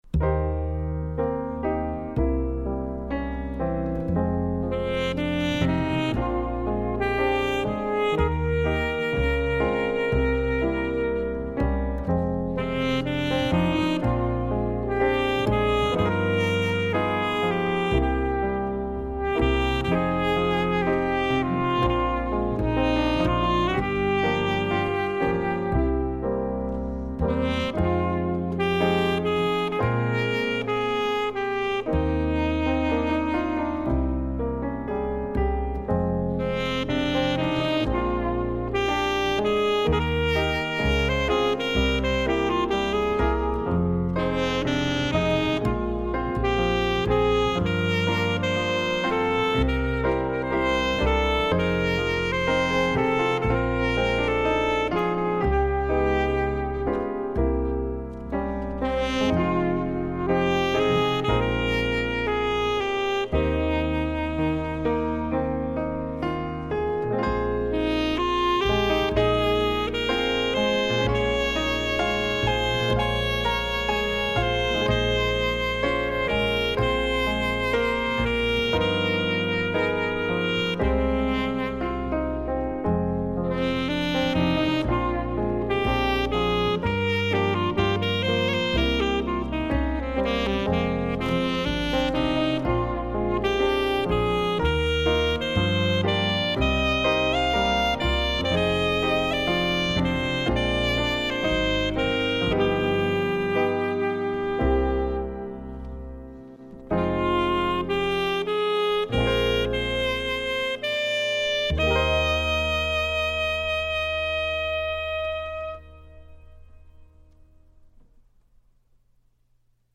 (Sax Trio)
(Piano, Double Bass, Sax)